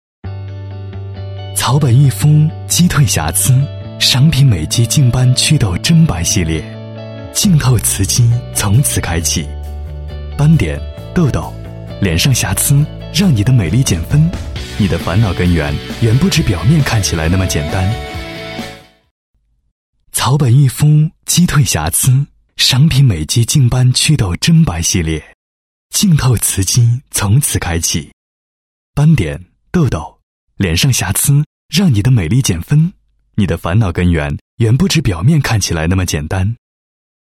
男32高端广告
男32 化妆品类型-赏品美肌（品质感）.mp3